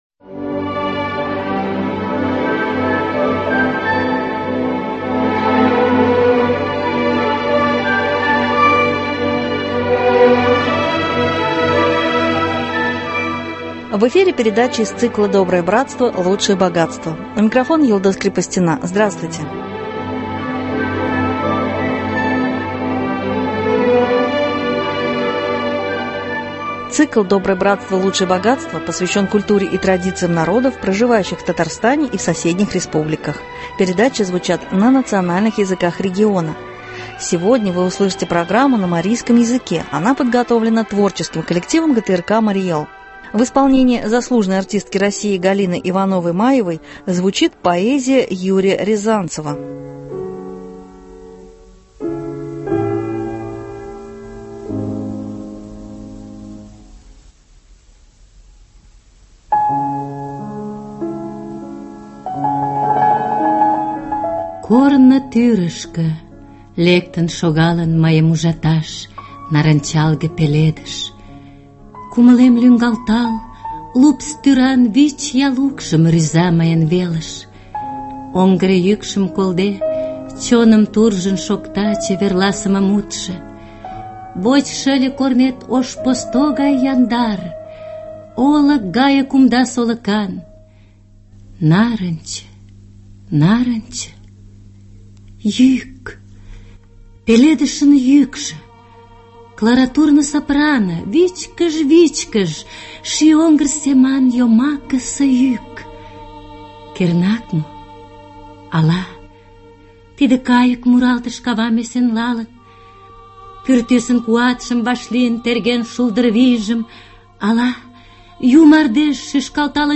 звучит поэзия Юрия Рязанцева